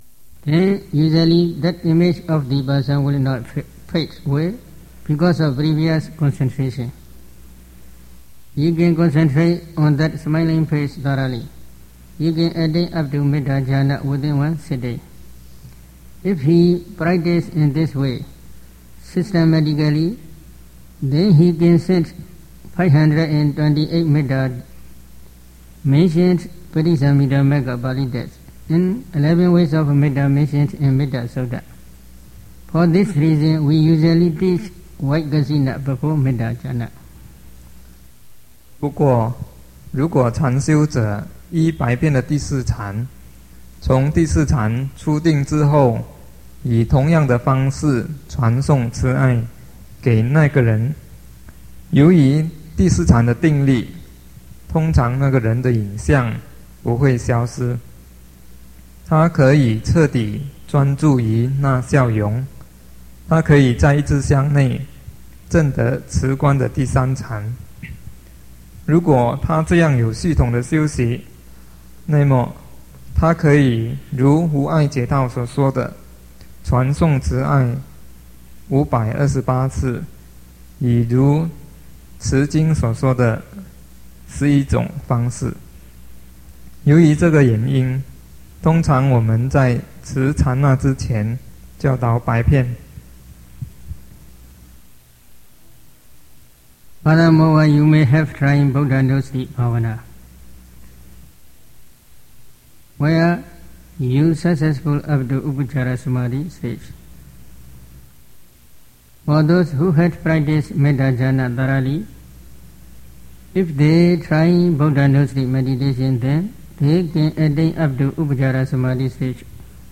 佛學講座
帕奧禪師 > 問答開示